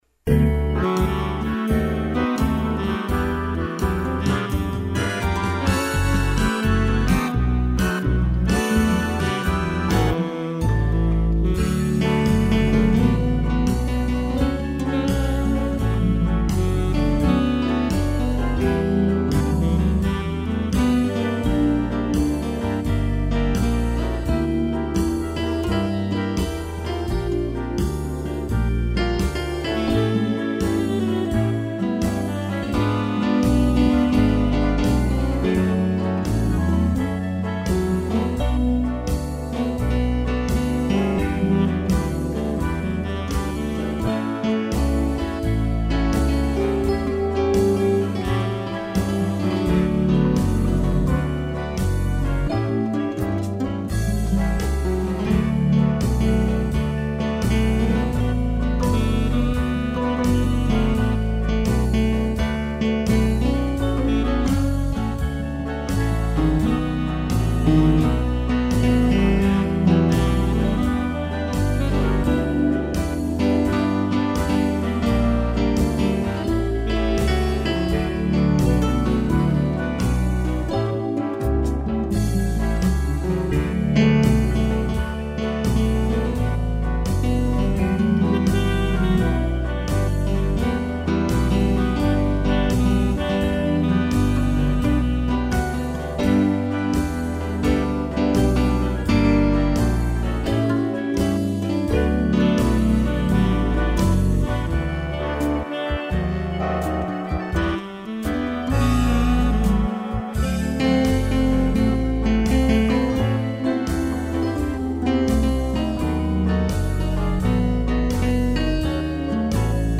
violão e sax